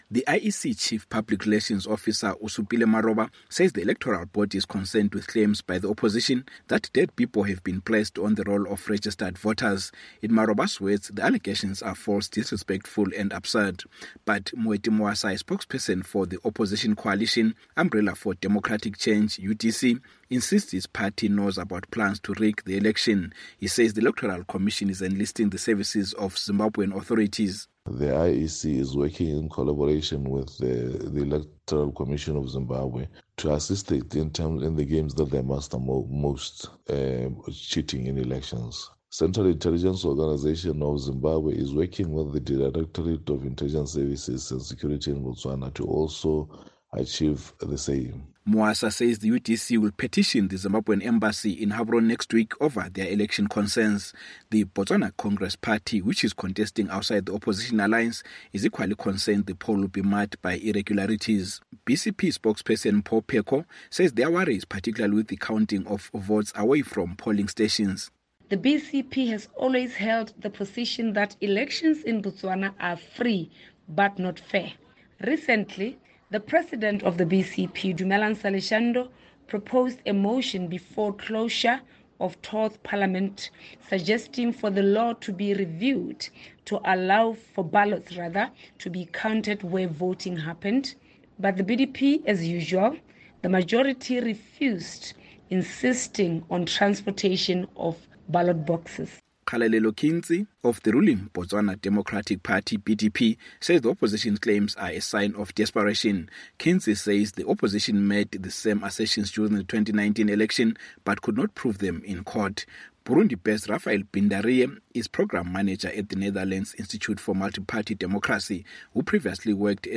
reports from Gaborone, Botswana